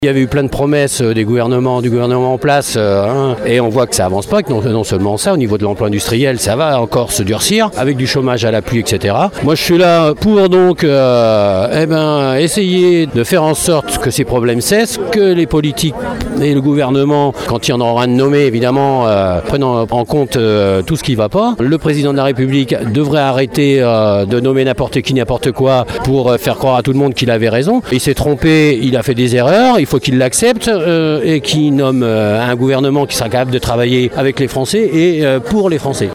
Manifestation hier à Rochefort.
manif-rft-3.mp3